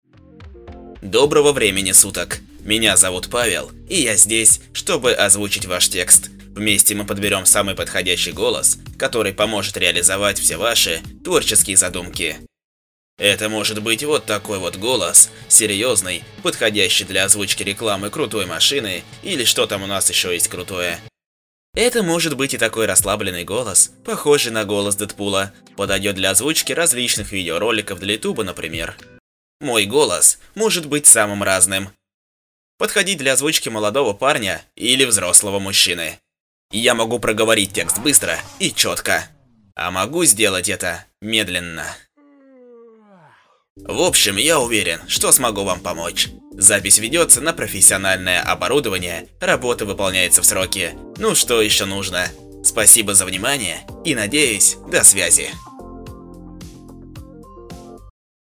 Пример звучания голоса
Демо
Муж, Другая/Средний